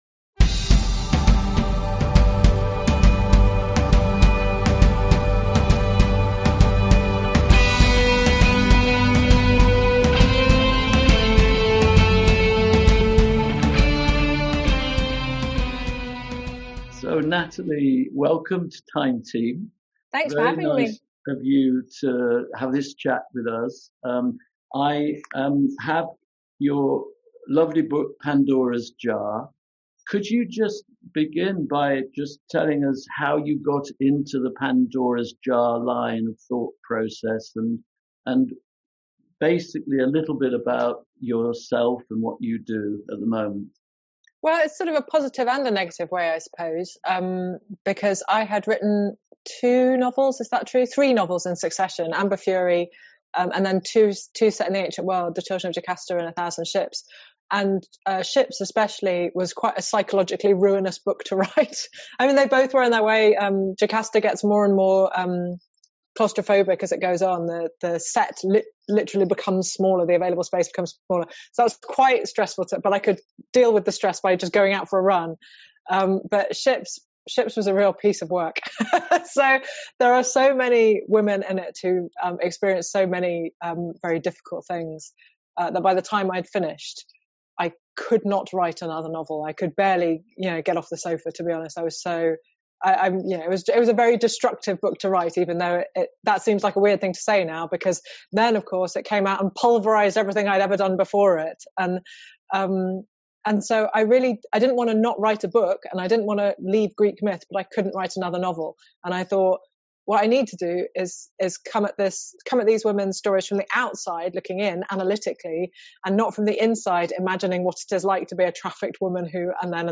We're honoured to be joined by Natalie Haynes – stand-up comedian, author, classicist and presenter of BBC Radio 4's much-loved 'Natalie Haynes Stands Up for the Classics'.